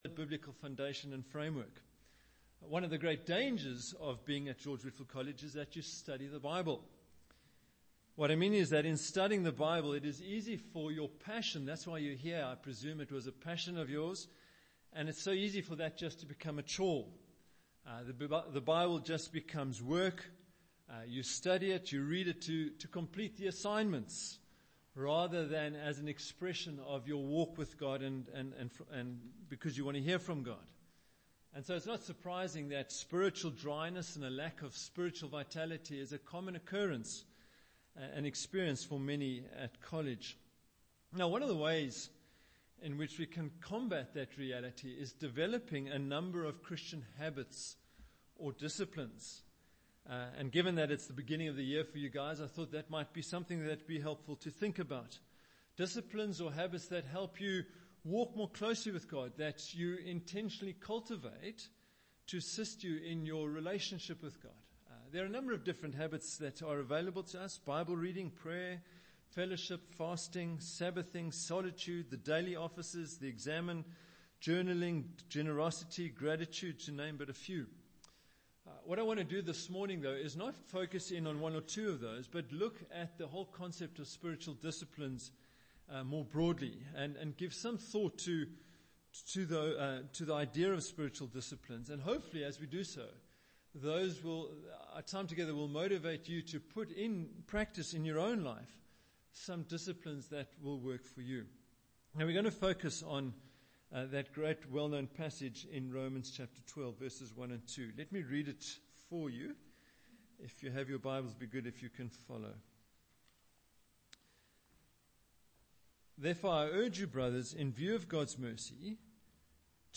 Romans 12:1-2 Service Type: Chapel Sermon Bible Text